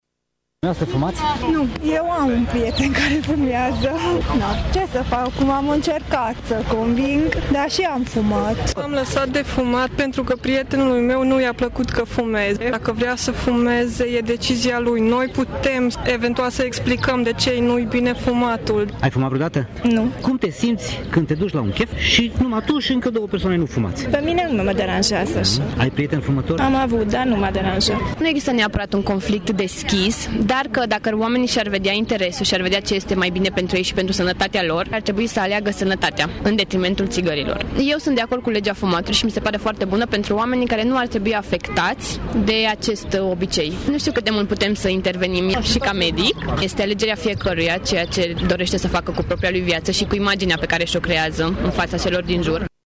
Târgumureșenii cu care am stat de vorbă, fumători sau nu, au fost de acord că a fuma este nociv, însă problema ține de modul în care fumatul marginalizează sau, dimpotrivă, apropie oamenii.